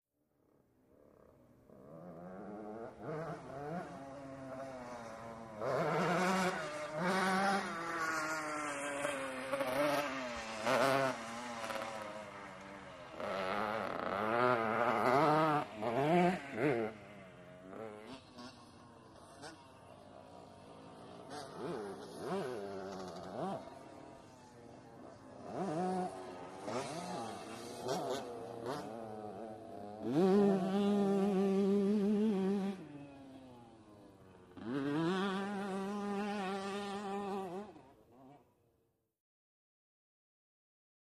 Dirt Bikes ( 2 ), Over Terrain with Revs And Various Action, Medium Distant.